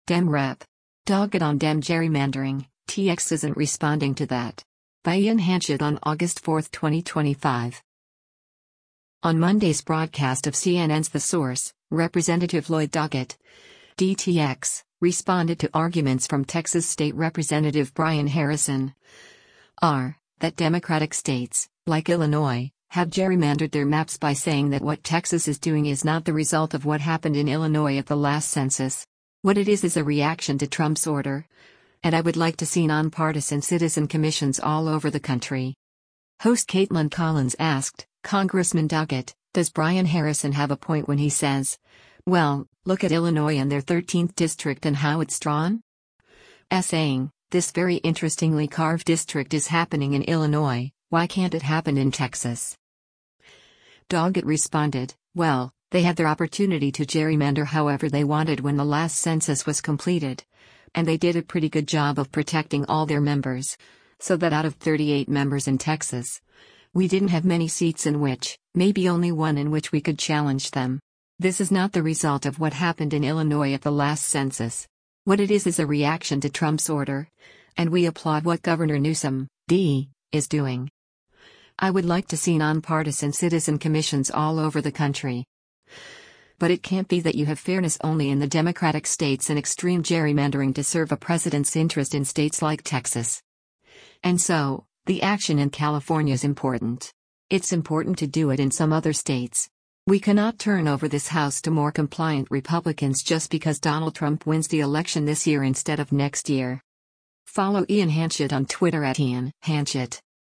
Host Kaitlan Collins asked, “Congressman Doggett…does Brian Harrison have a point when he says, well, look at Illinois and their 13th district and how it’s drawn? … [S]aying, this very interestingly-carved district is happening in Illinois, why can’t it happen in Texas?”